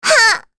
Rehartna-Vox_Damage_04.wav